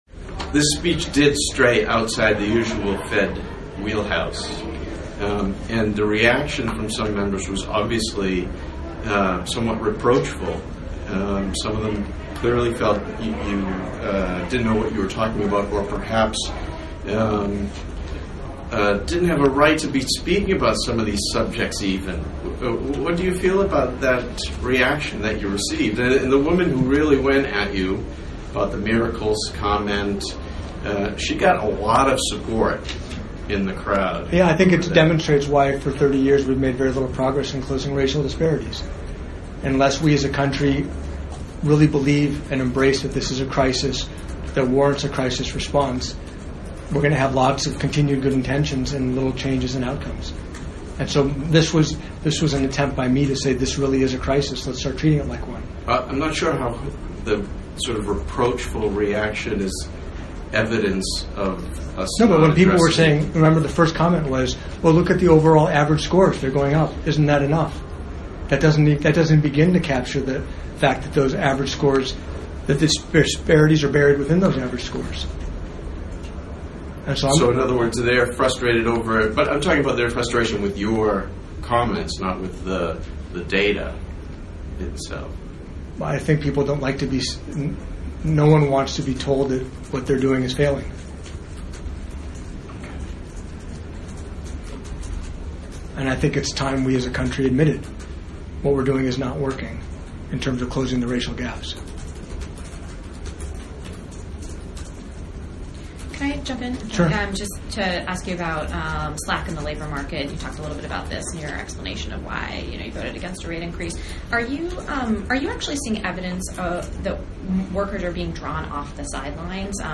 Neel Kashkari's remarks given at the Tenth Biennial Federal Reserve System Community Development Research Conference